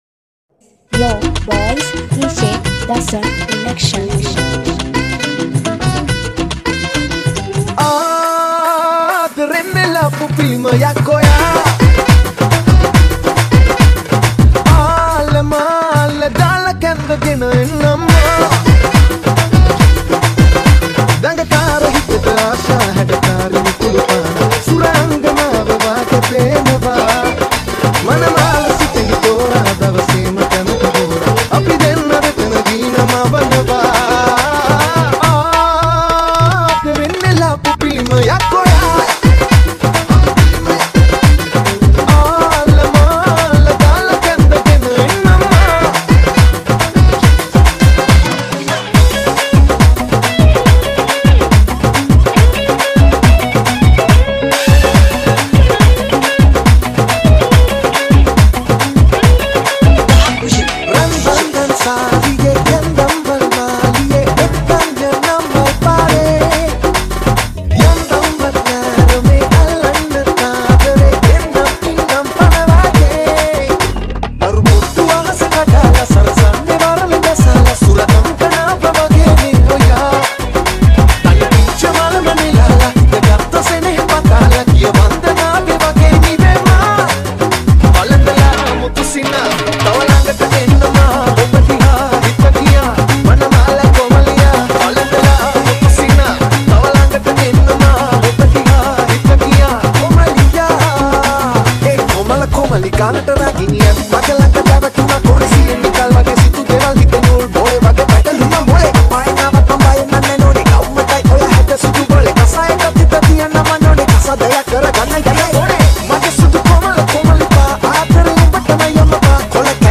Remix Kawadi Dance